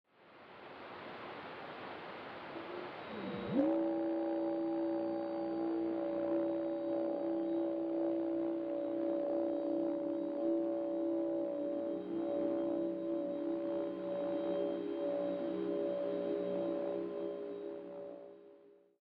Here is a link for a .mp4 movie. 1 MB The radio reflection was about one minute long but I just included the head echo and twenty seconds or so of forward scatter.